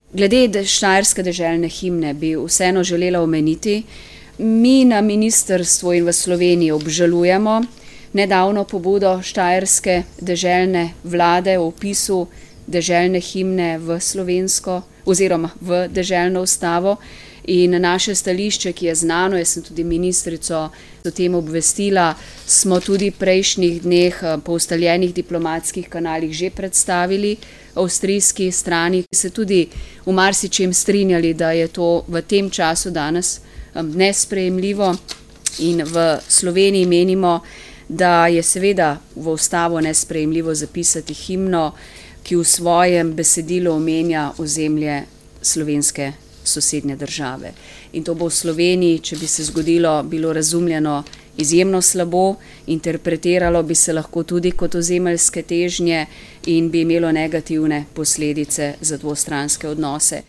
Odnose med Slovenijo in Avstrijo je  pred prazniki obremenila namera štajerske deželne vlade, da v deželno ustavo zapiše himno, ki izvira iz 19. stoletja in opeva ozemlja na območju današnje Slovenije. Slovenska zunanja ministrica Tanja Fajon: